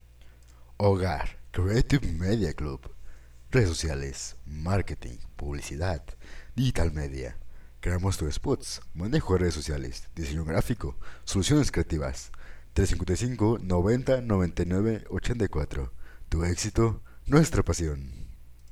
Looking for a latin accent?
From an enthusiastic voice to a serious and elegant one.
spanisch
Sprechprobe: Industrie (Muttersprache):